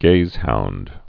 (gāzhound)